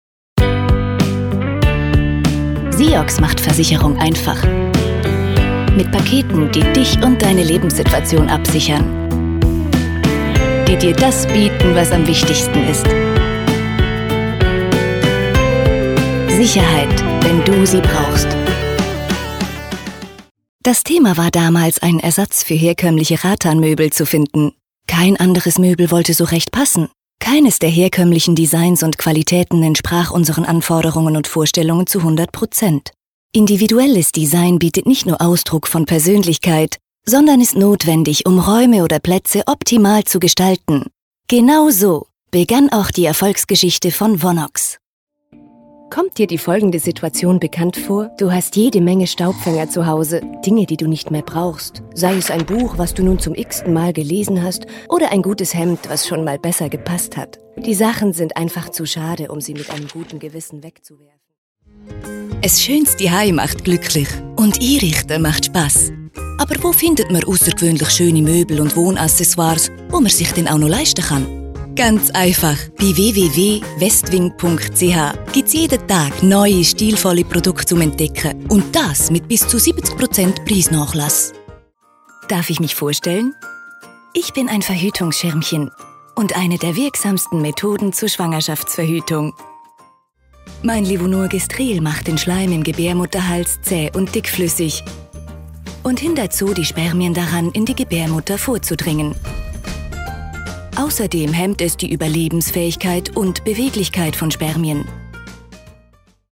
Fundiert ausgebildete Sprecherin, Schauspielschule, Native Speaker auch für Schweizer Dialekte, Sprecherin, mittel bis tiefe Stimme, Hamburg, Schweiz, TV- und Radio Spots, Voice Over, Imagefilme, Industriefilme, E-Learnings, Synchron, Computerspiele
Sprechprobe: Industrie (Muttersprache):